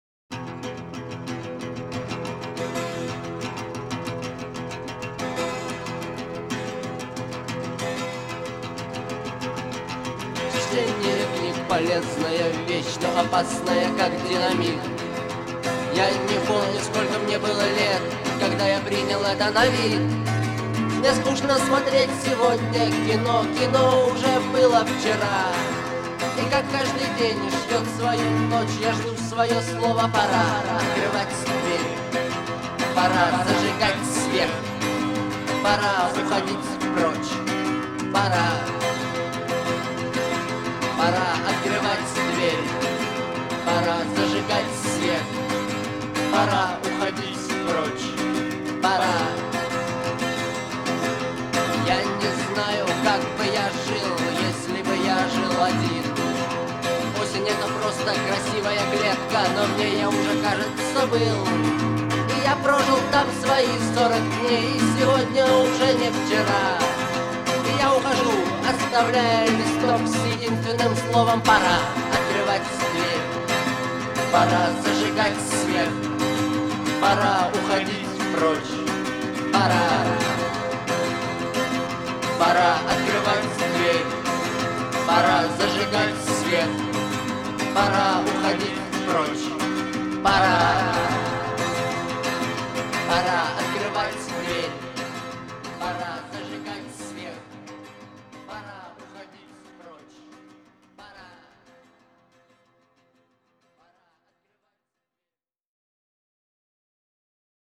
характерными гитарными рифами